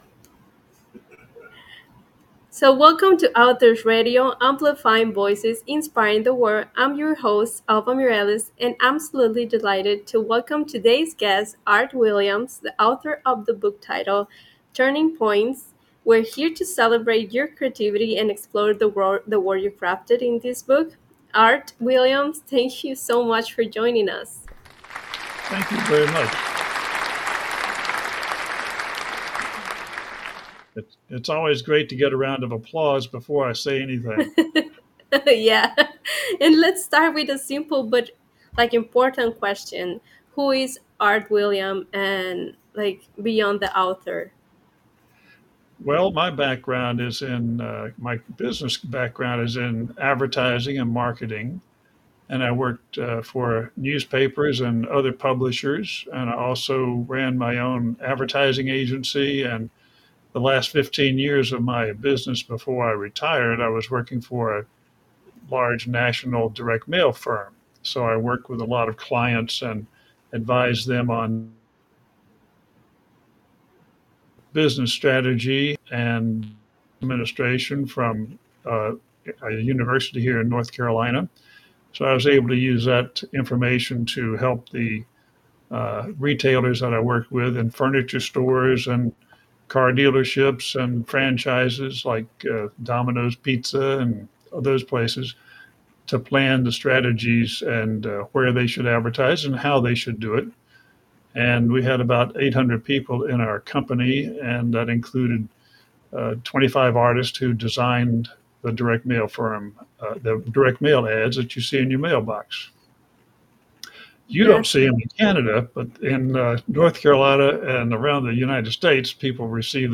NEW INTERNATIONAL PODCAST